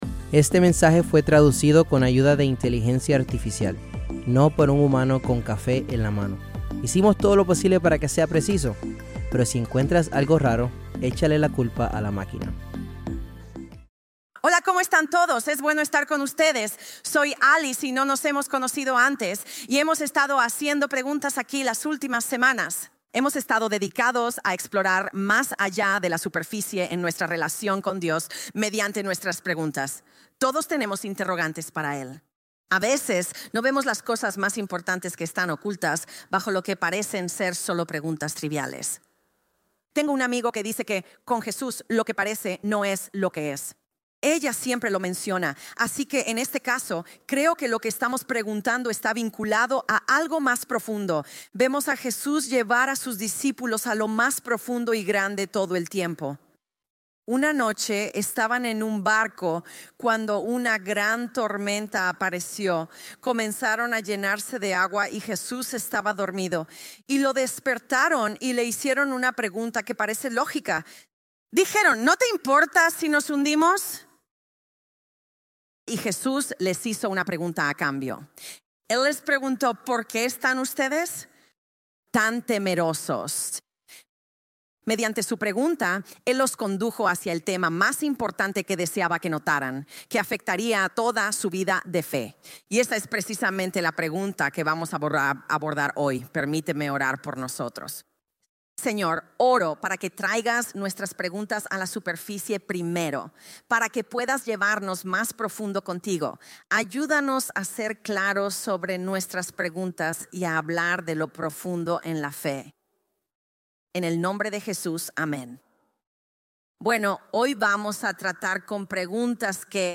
Grabado en vivo en la Iglesia Crossroads de Cincinnati, Ohio.